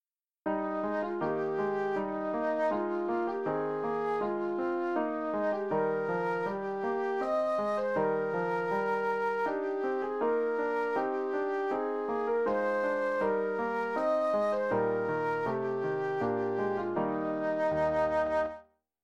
058.b-Fjólan (solo+piano)